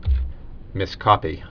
(mĭs-kŏpē)